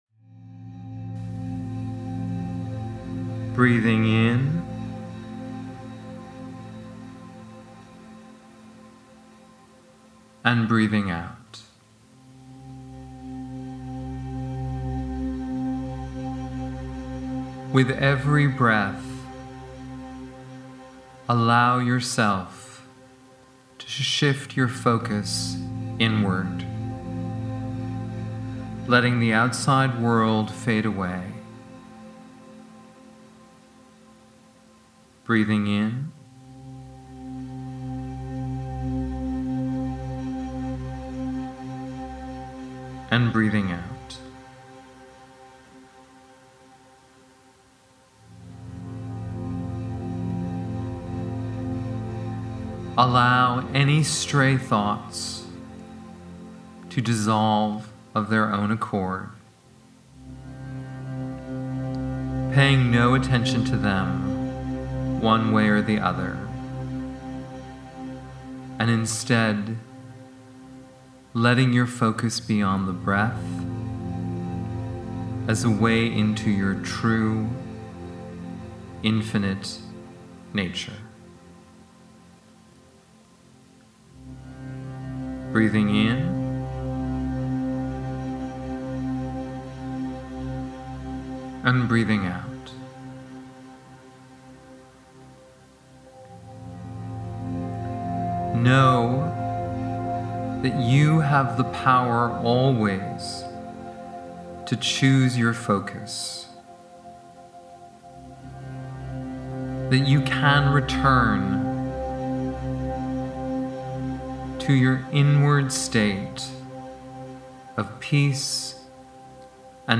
This guided meditation is 8 minutes long with an extra 10 minutes more of music